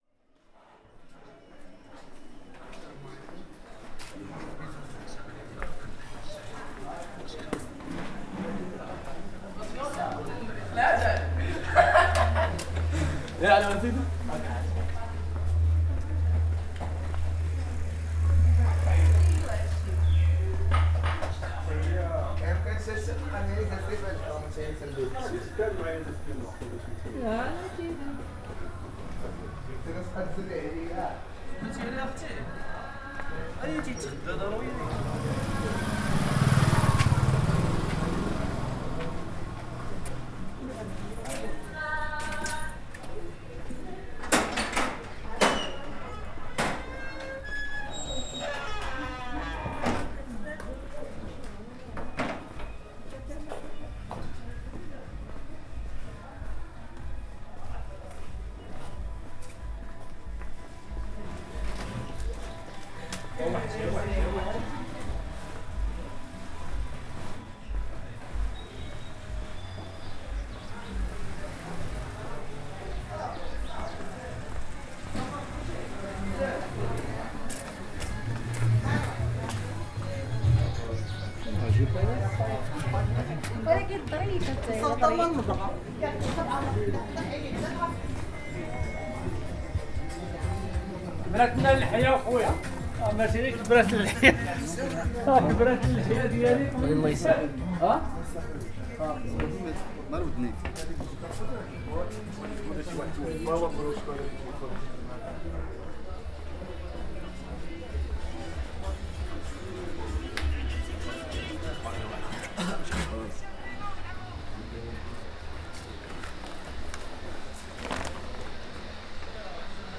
Our guest house was in the old town, and I wanted to show the vibrance of the alleyways, so I took a walk from one side to the other, with my stereo recorder held in front of me. The result is best on headphones:
meknes_walk_through_medina.mp3